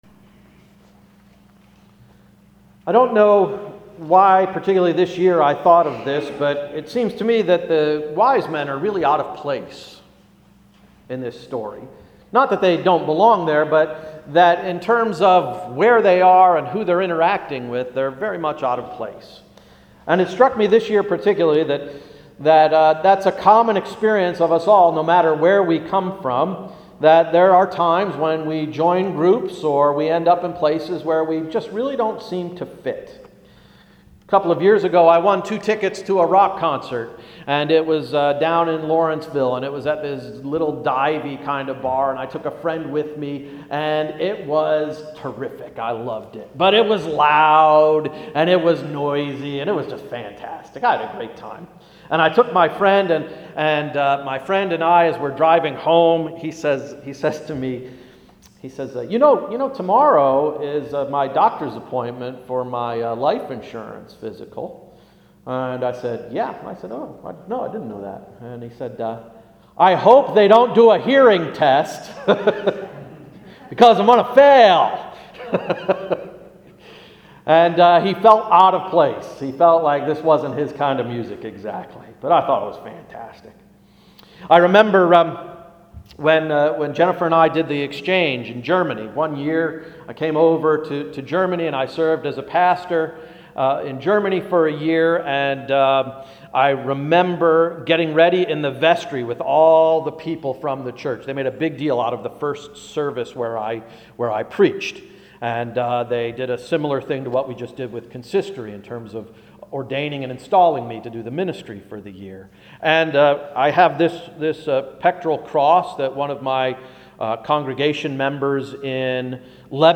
Sermon of January 10–“King Me!”